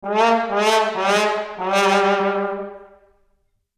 Download Funny Cartoon sound effect for free.
Funny Cartoon